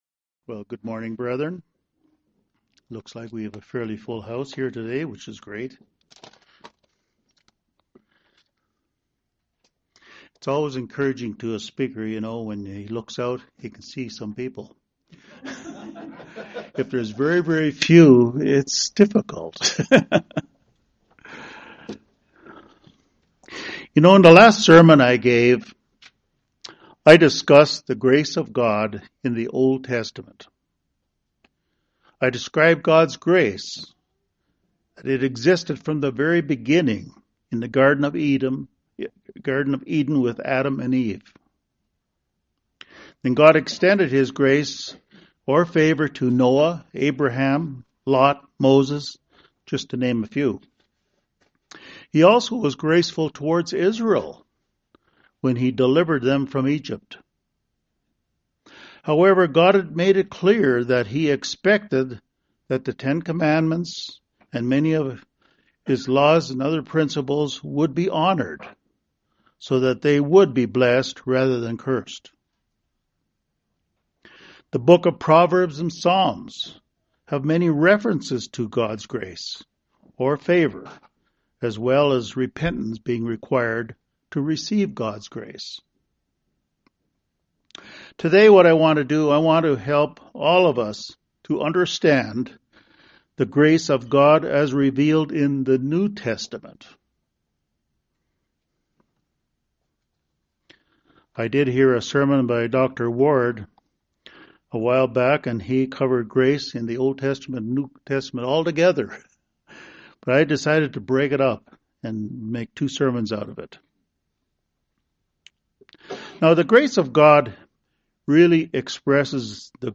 This sermon describes how grace is involved in our calling, our belief in Jesus Christ, repentance, forgiv
Given in Denver, CO